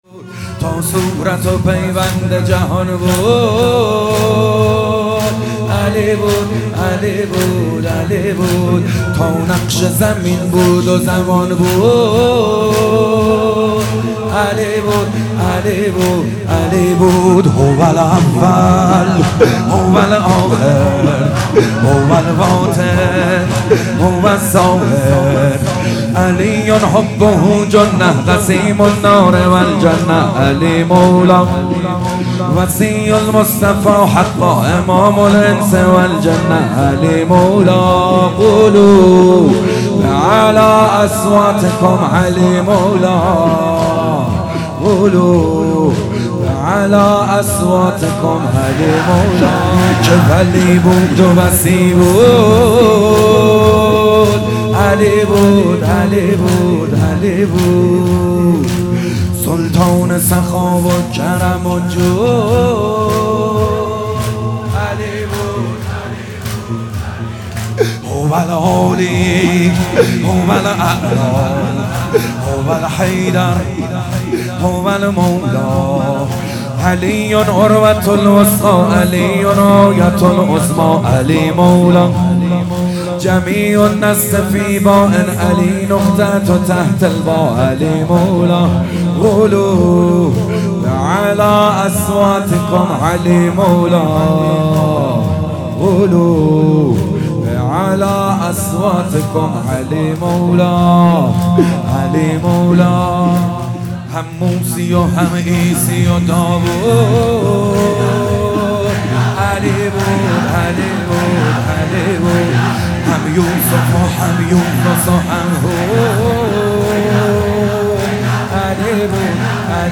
مداحی محمدحسین حدادیان | ایام فاطمیه 1440 | محفل عزاداران حضرت زهرا (س) شاهرود | پلان 3